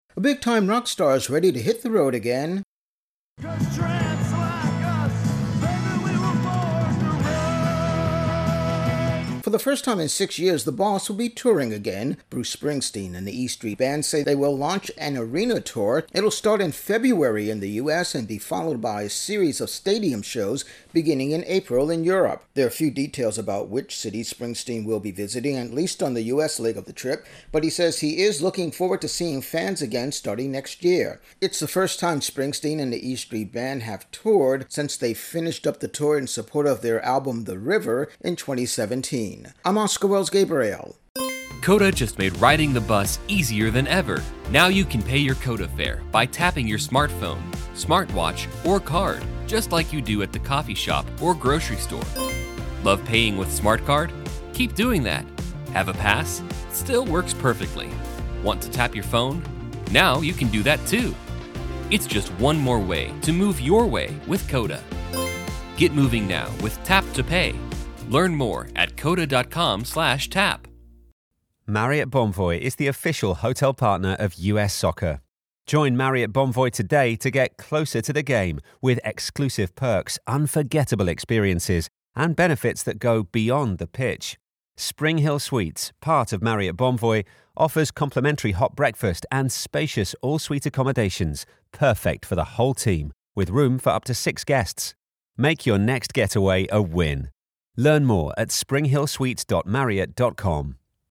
Intro+wrap on Bruce Springsteen tour announcement